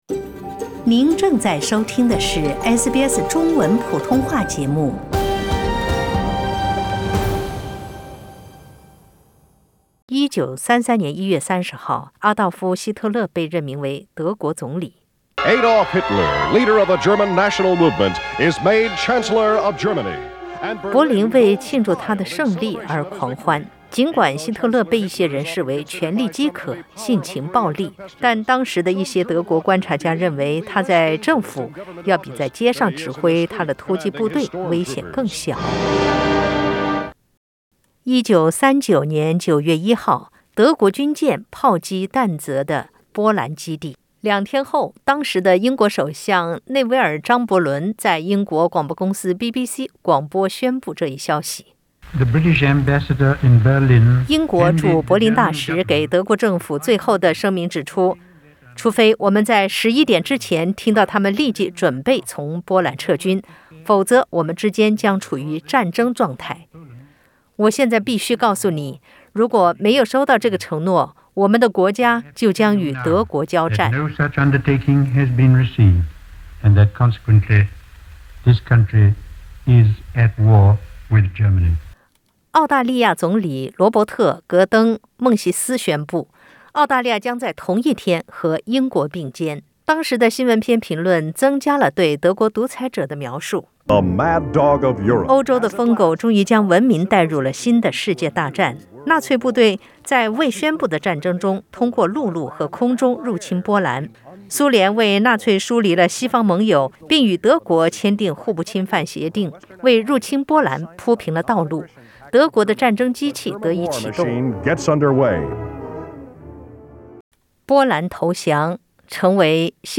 第二次世界大戰始於1939年9月，結束於75年前的歐洲。1945年5月8日是一個改變世界版圖、影響幾代人命運的日子。點擊上圖收聽錄音報道。